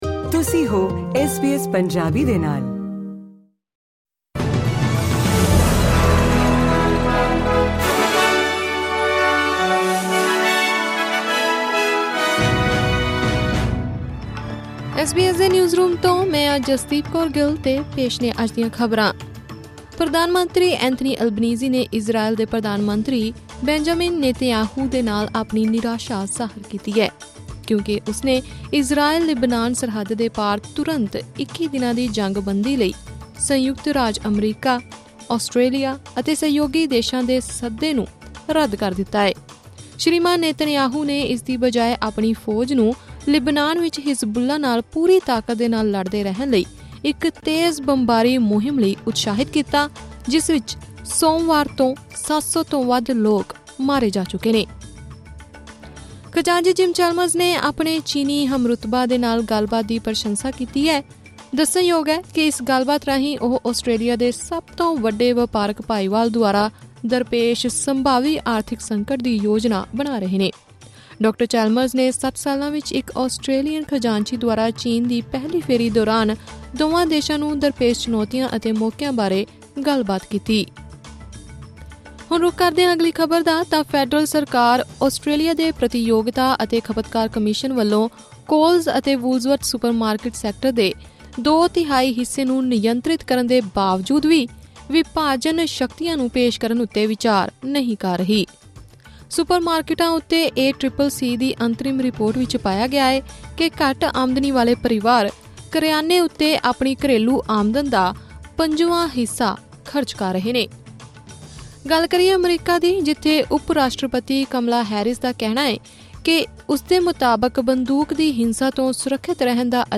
ਐਸ ਬੀ ਐਸ ਪੰਜਾਬੀ ਤੋਂ ਆਸਟ੍ਰੇਲੀਆ ਦੀਆਂ ਮੁੱਖ ਖ਼ਬਰਾਂ: 27 ਸਤੰਬਰ 2024